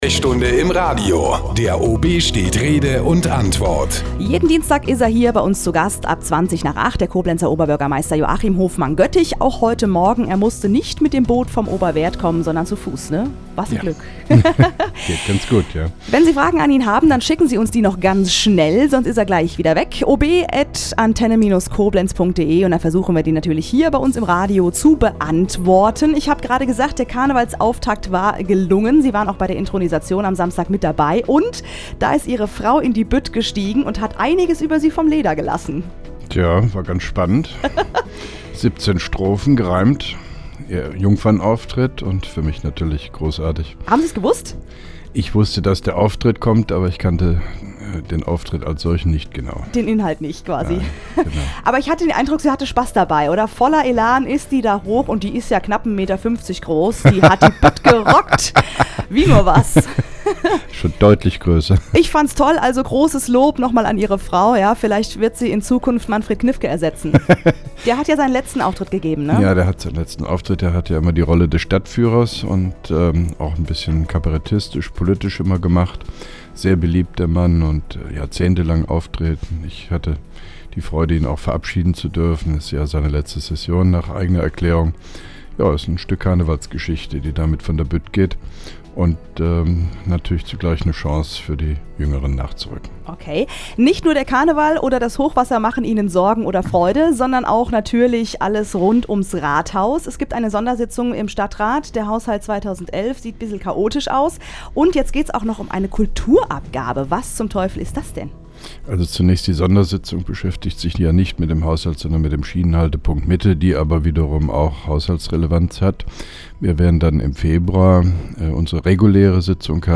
(2) Koblenzer Radio-Bürgersprechstunde mit OB Hofmann-Göttig 11.01.2011
Interviews/Gespräche